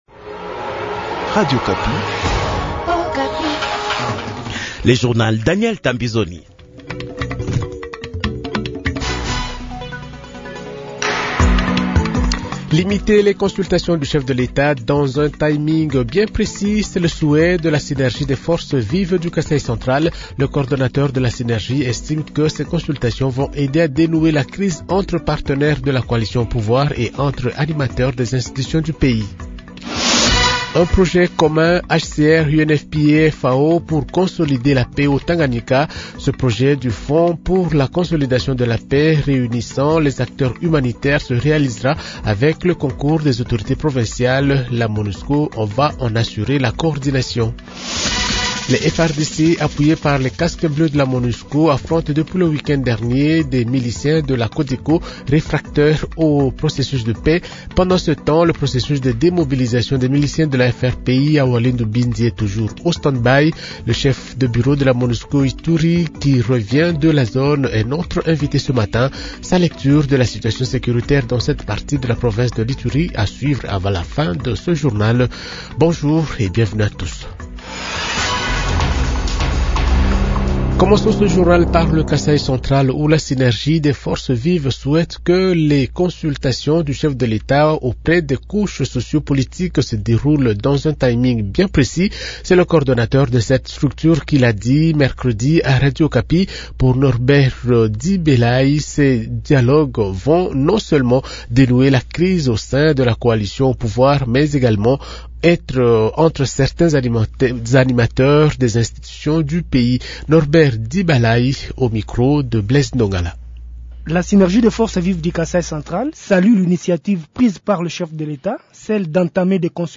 Journal Francais 8h00